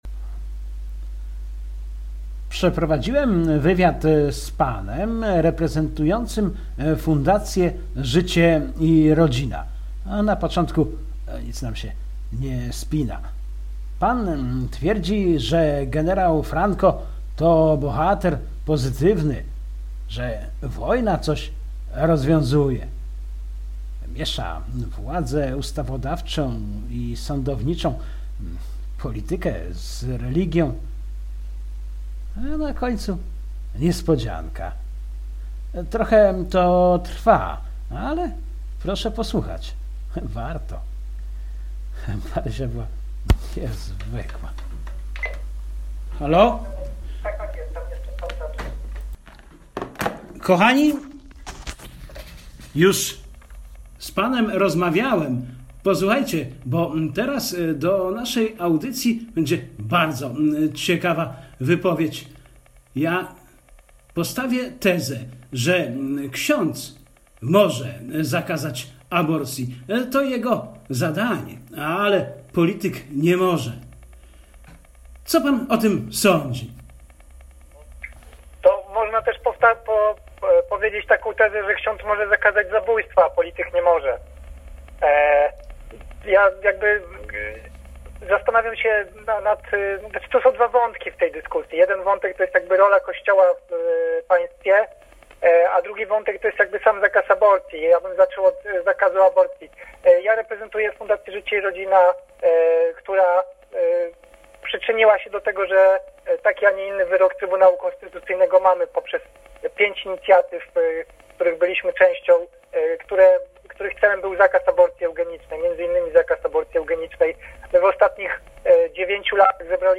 Niezwykła-M-i-wywiad.mp3